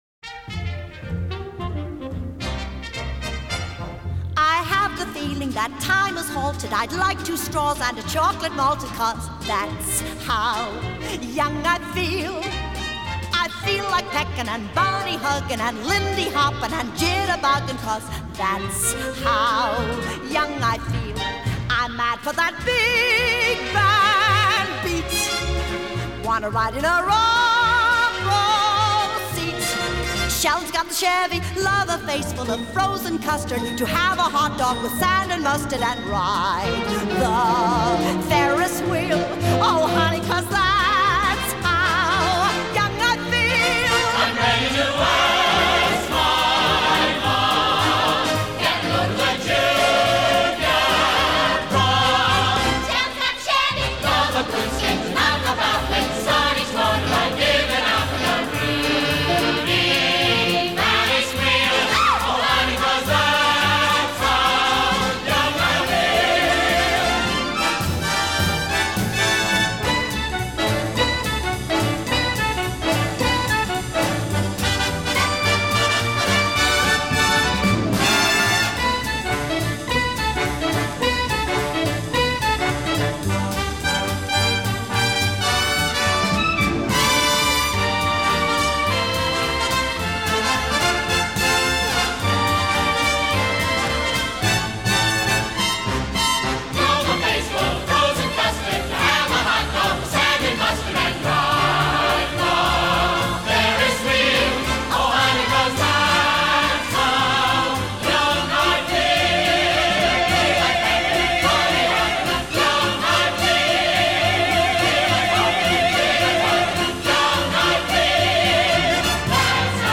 1966   Genre: Musical   Artist